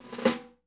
B5RK  ROLL.wav